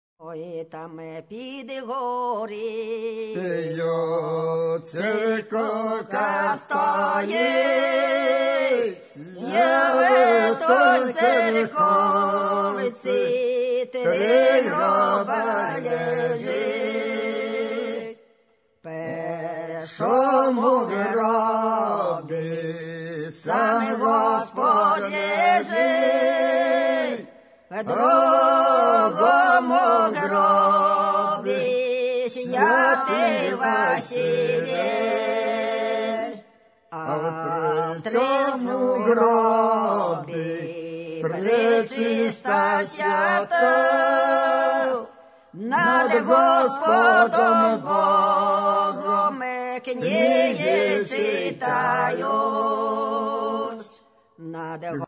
Authentic Performing